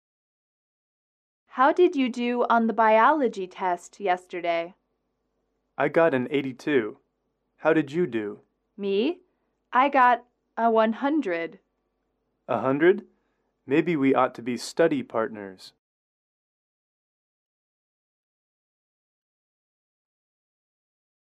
英语口语情景短对话07-4：生物考试